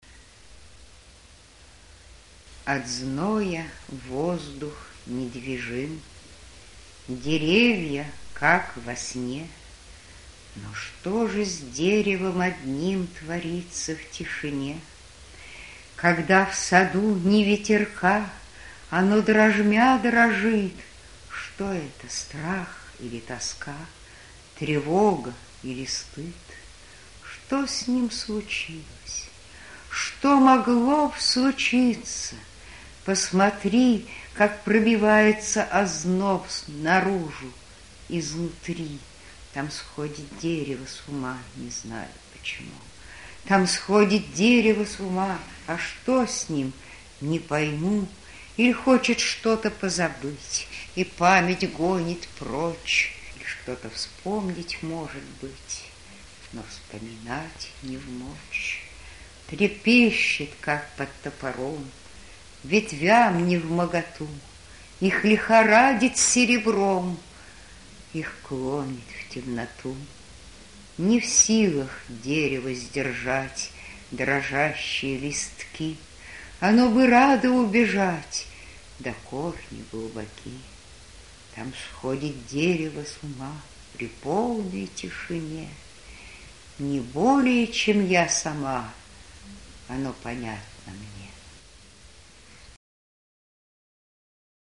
1. «Мария Петровых – Дальнее дерево Читает автор» /
Petrovyh-Dalnee-derevo-Chitaet-avtor-stih-club-ru.mp3